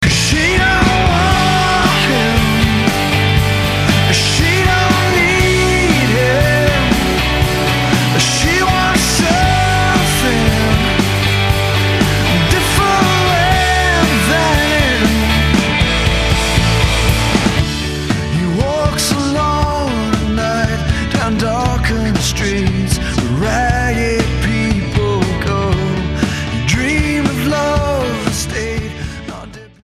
STYLE: Hard Music
has a pleasing radio-friendly feel